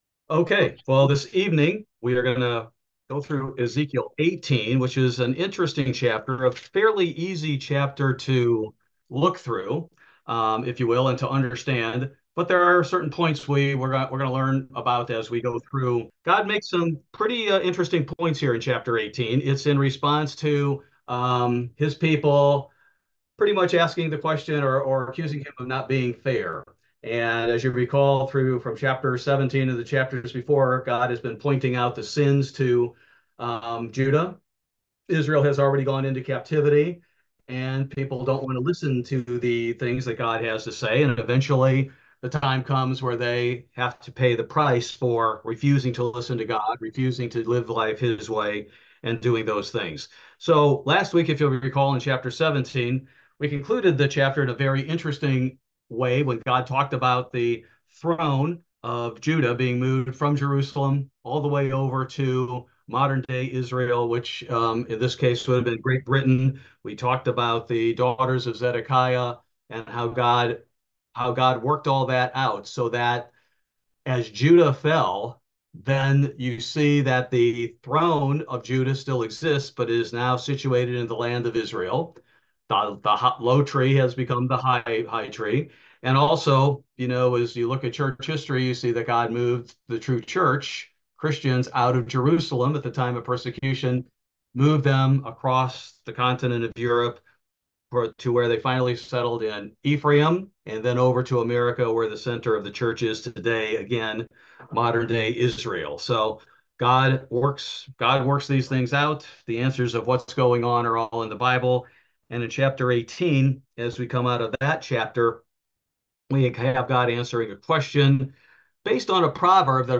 Bible Study: September 18, 2024